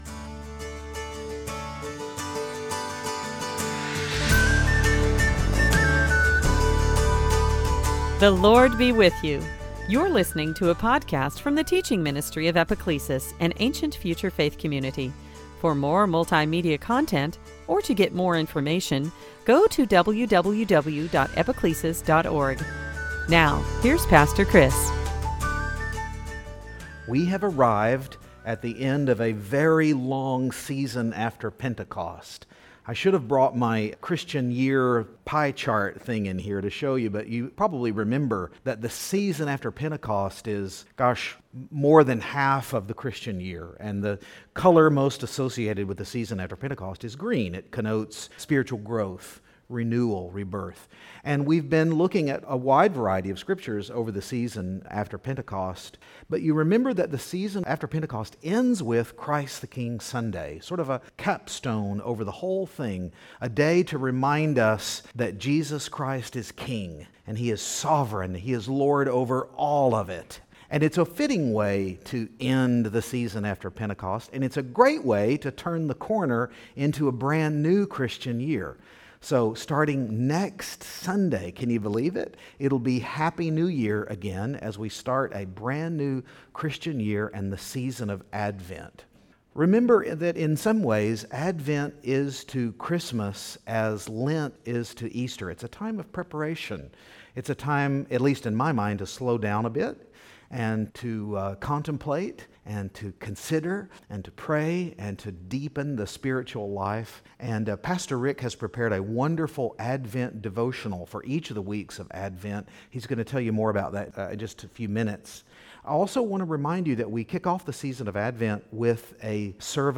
Series: Sunday Teaching A good king is hard to find